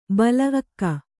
♪ bala akka